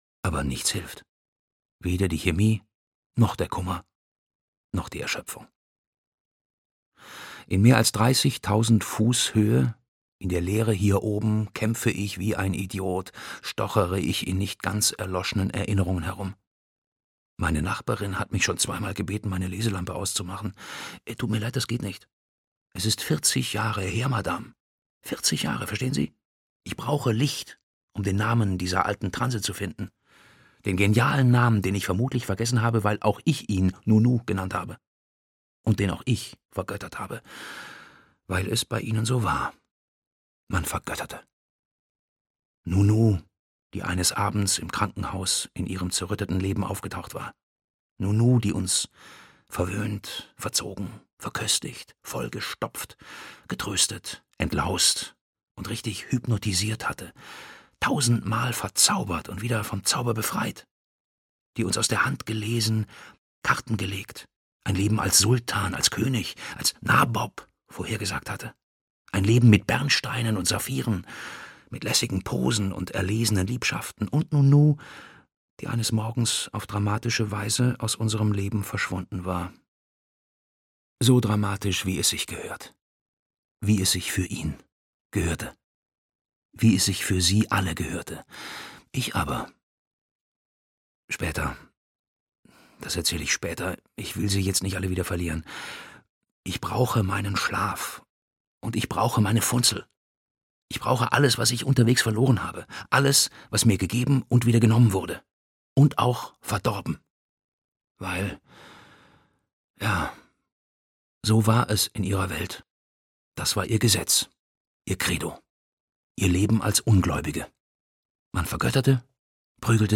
Hörbuch Alles Glück kommt nie, Anna Gavalda.